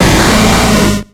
Cri de Smogogo dans Pokémon X et Y.